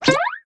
poco_new_reload_02.wav